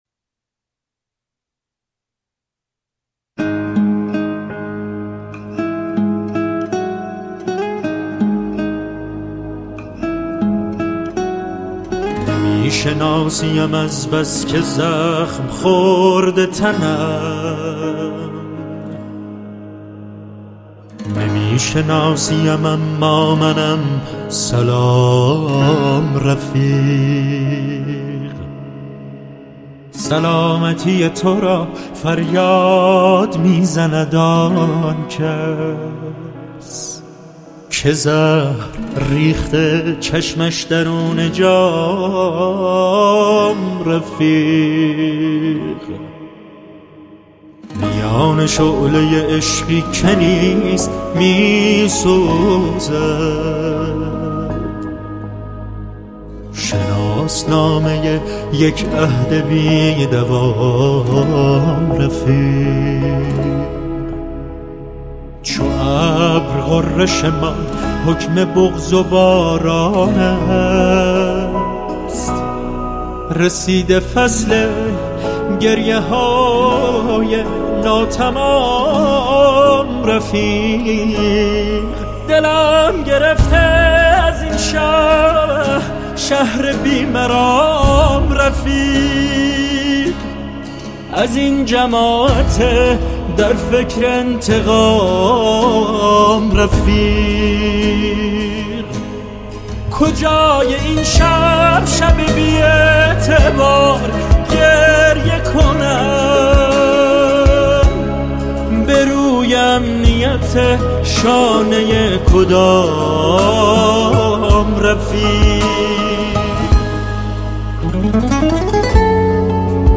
با ریتم ۶/۸ سنگین